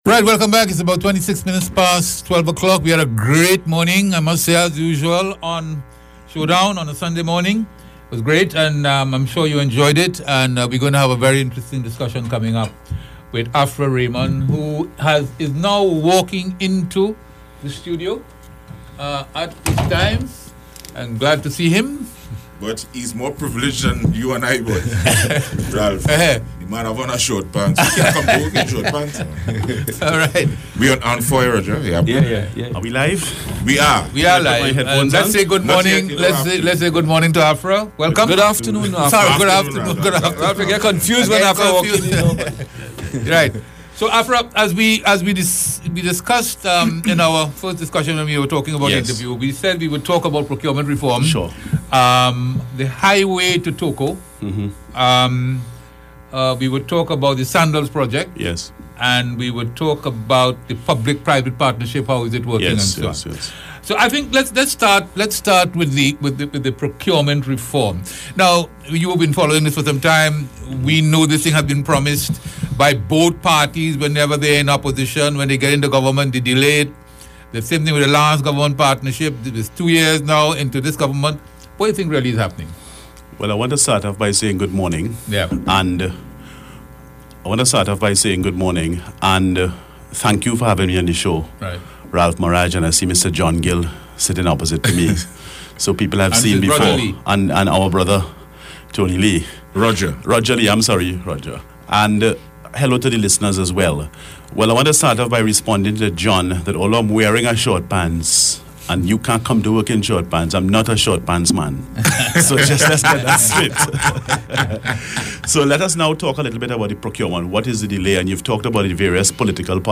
AUDIO: Interview on Showdown on i95.5 FM – 26 November 2017
It was an extensive and robust discussion, with significant call-ins and listener interaction.